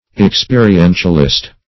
Search Result for " experientialist" : The Collaborative International Dictionary of English v.0.48: Experientialist \Ex*pe`ri*en"tial*ist\ ([e^]ks*p[=e]`r[i^]*[e^]n"shal*[i^]st), n. One who accepts the doctrine of experientialism.